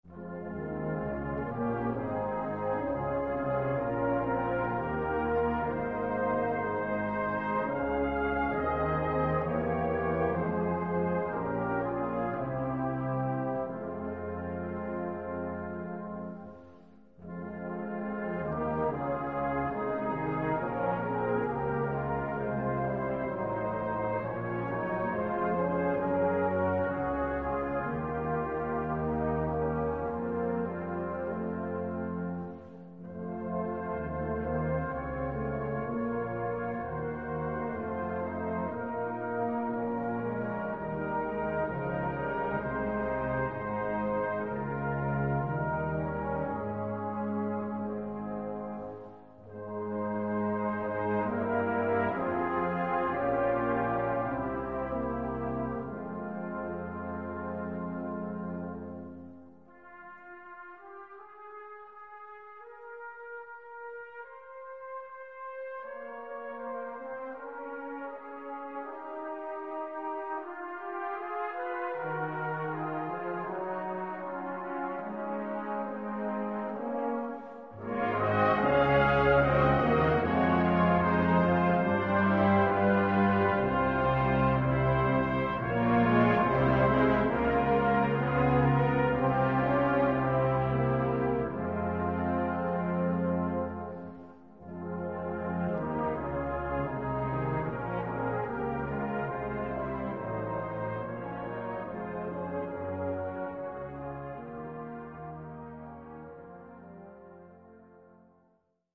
Gattung: Kirchenmusik
Besetzung: Blasorchester